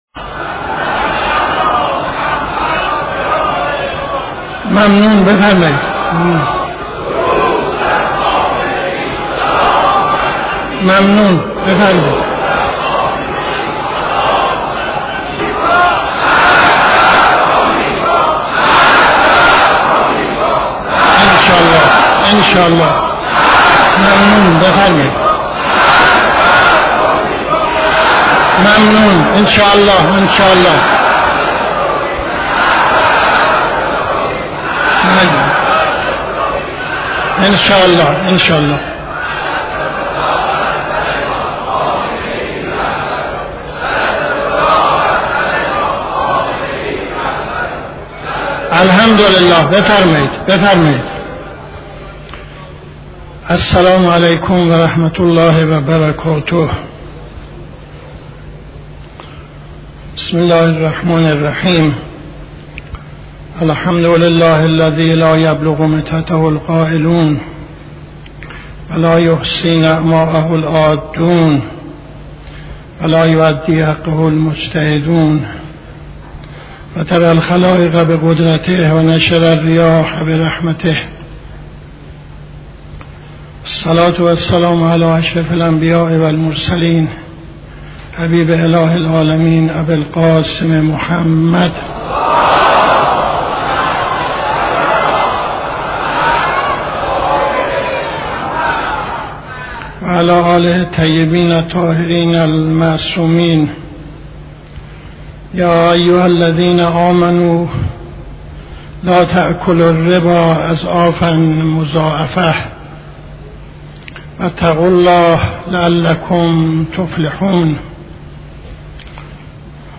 خطبه اول نماز جمعه 12-08-74